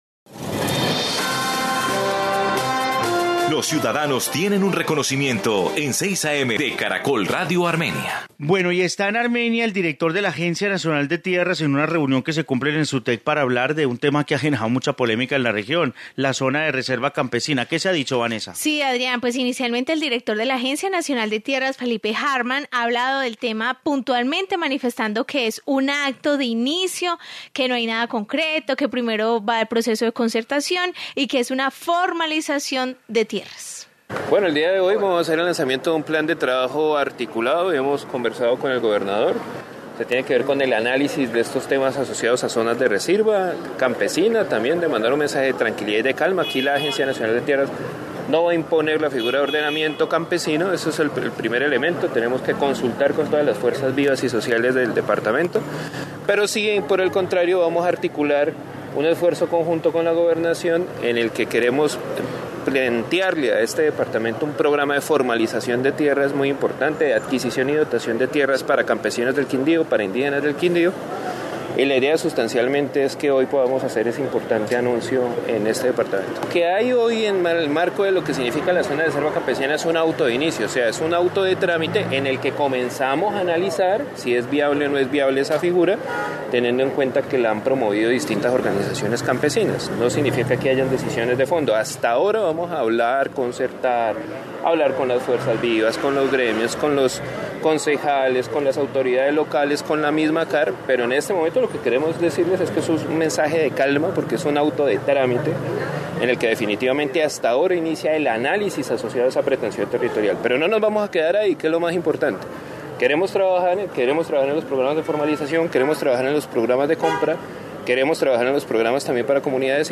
Informe sobre zona de reserva campesina